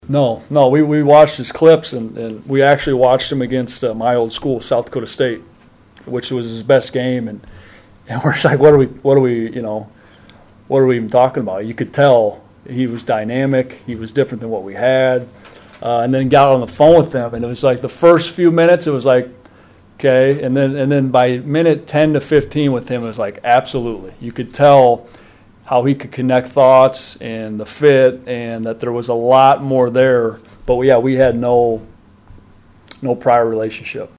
POSTGAME PRESS CONFERENCE EXCERPTS